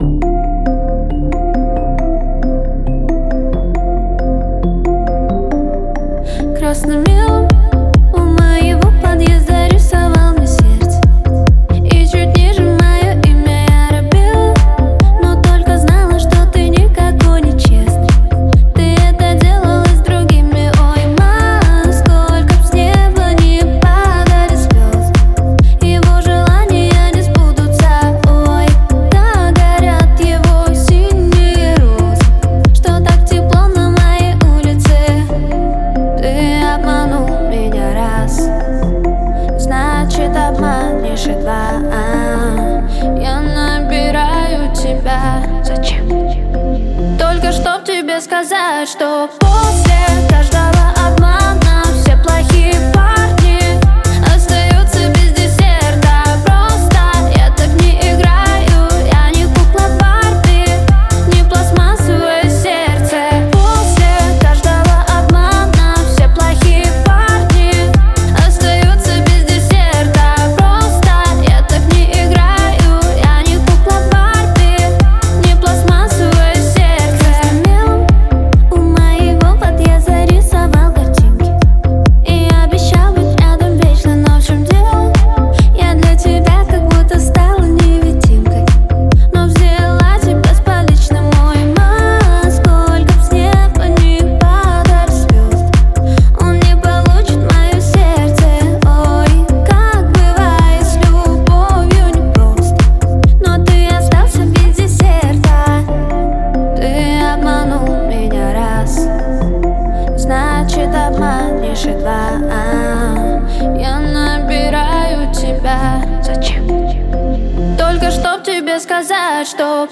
это эмоциональная песня в жанре поп с элементами инди